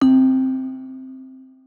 Bell - Distrorded.wav